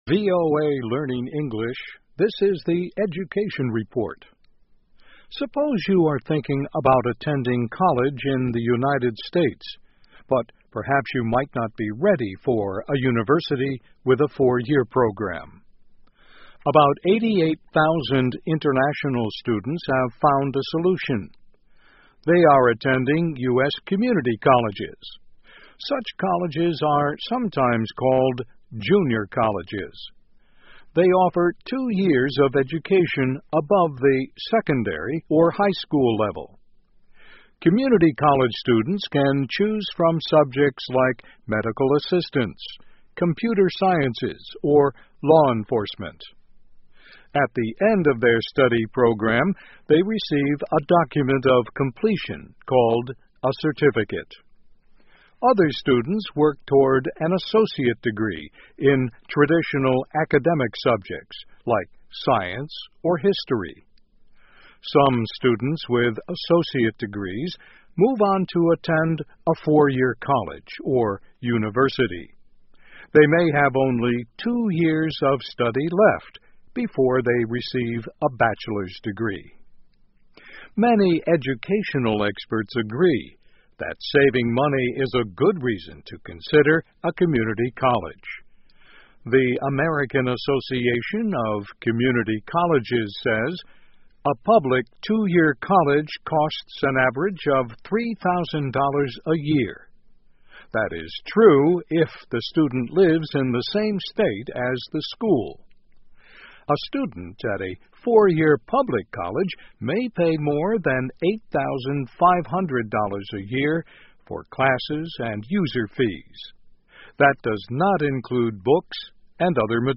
VOA慢速英语2014 Community Colleges in the United States 美国社区学院介绍 听力文件下载—在线英语听力室